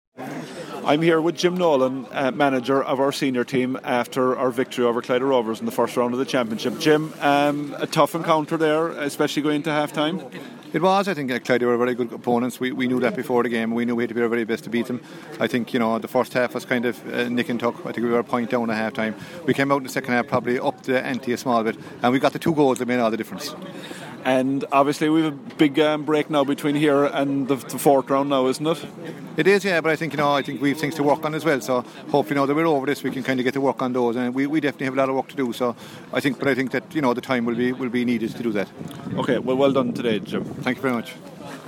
Postmatch interview